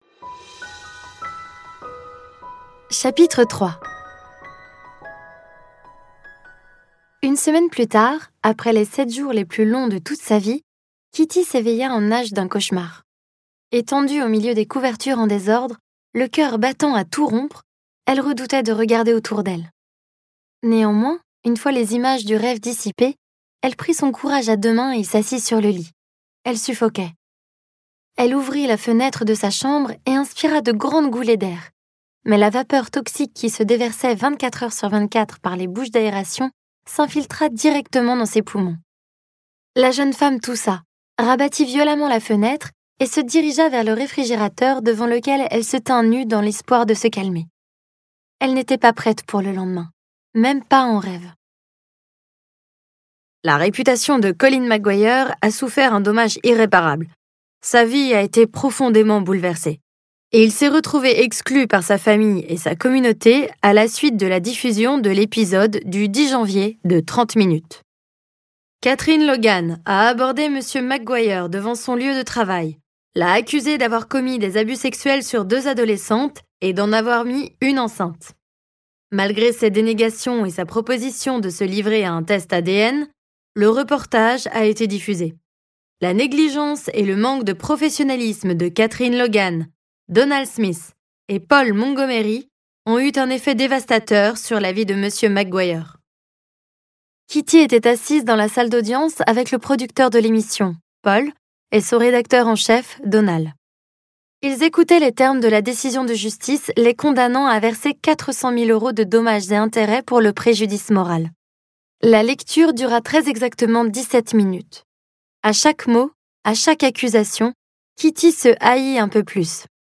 » - BooklistCe livre audio est interprété par une voix humaine, dans le respect des engagements d'Hardigan.